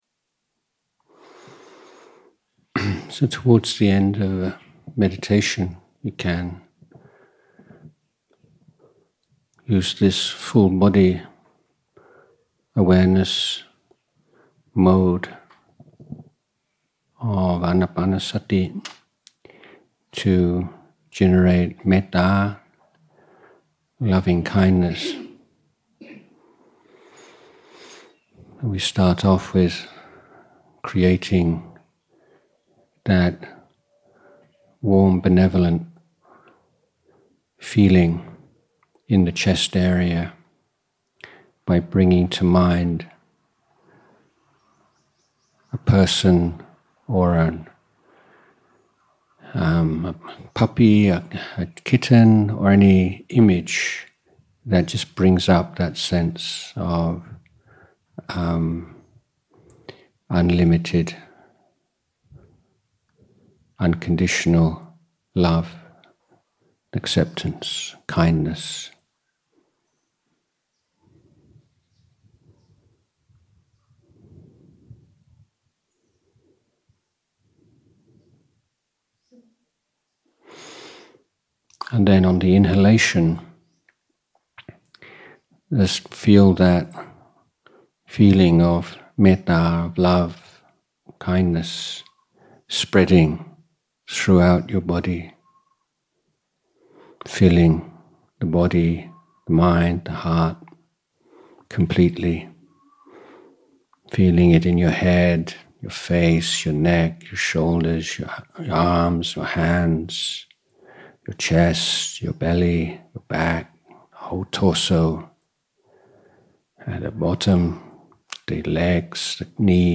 English Retreat at Bahn Boon, Pak Chong, Nakhon Rachasima, 20-24 March 2024